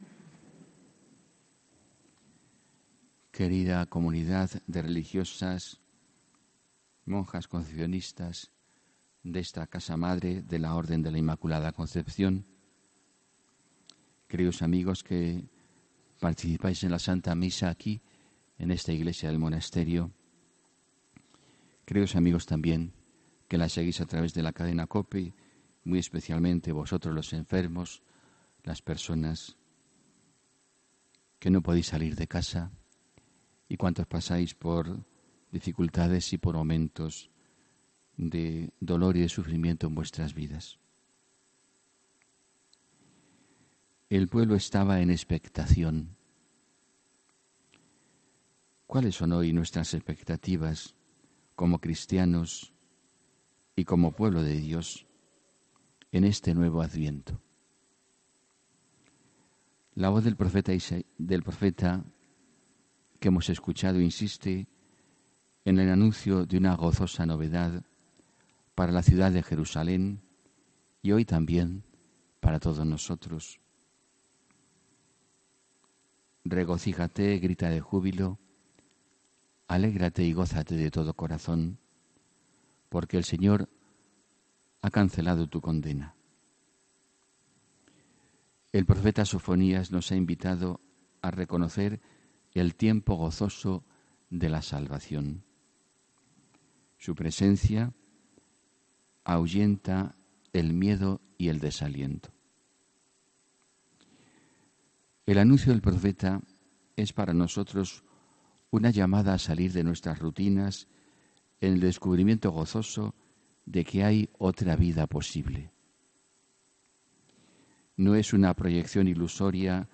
HOMILÍA 16 DICIEMBRE 2018